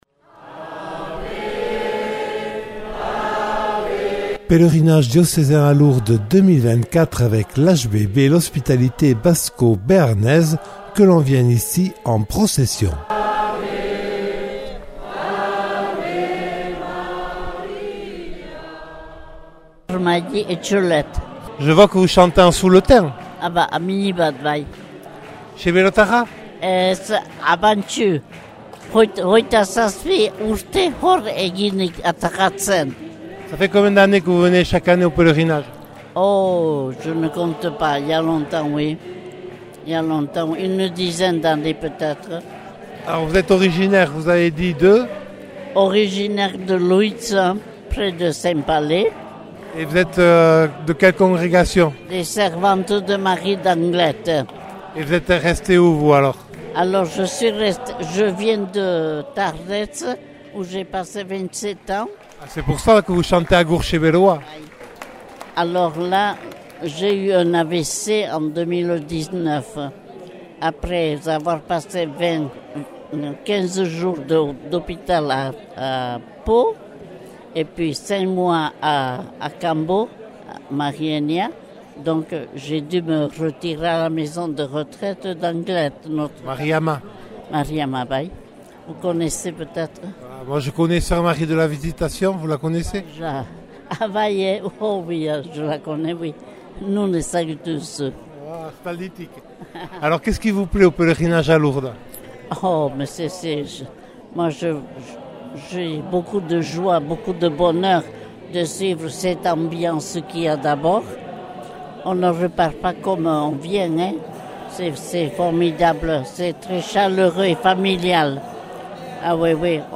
3ème reportage